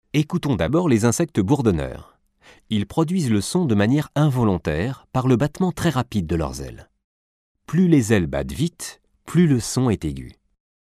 boudonant.mp3